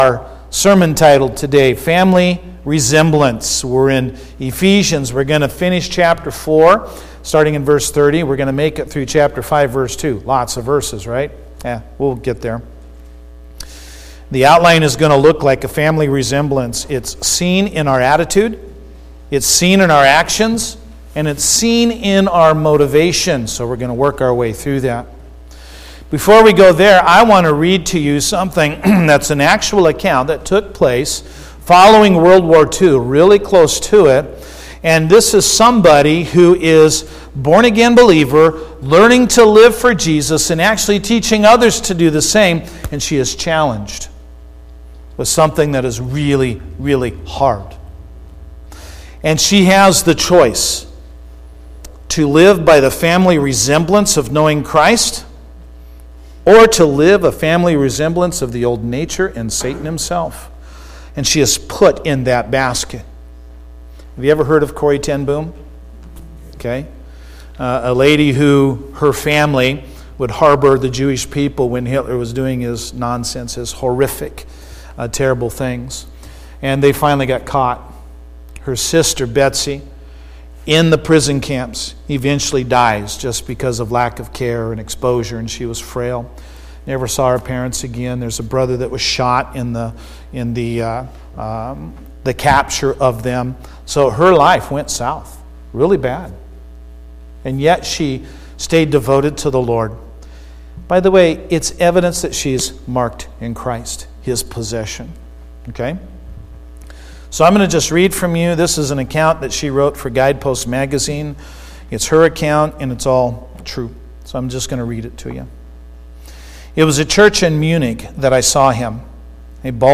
Mother’s Day Sermon